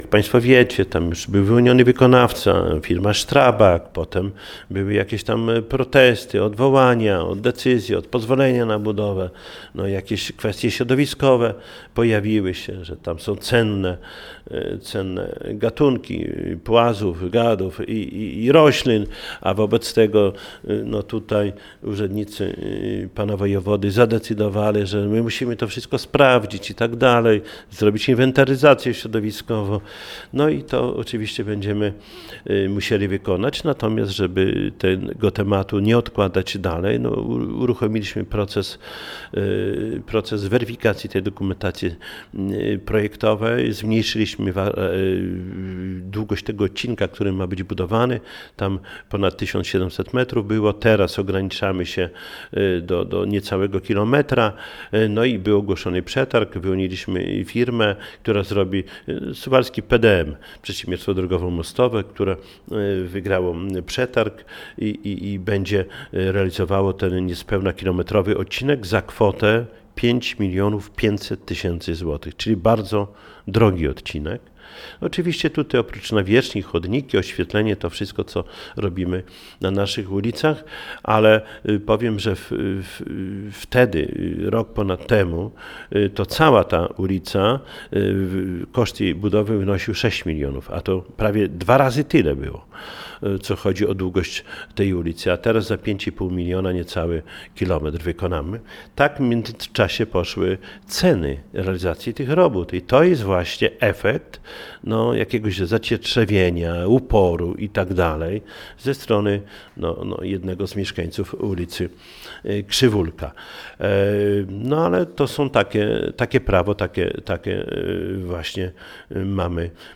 O szczegółach mówił w piątek (14.09) w Radiu 5 Czesław Renkiewicz, prezydent Suwałk.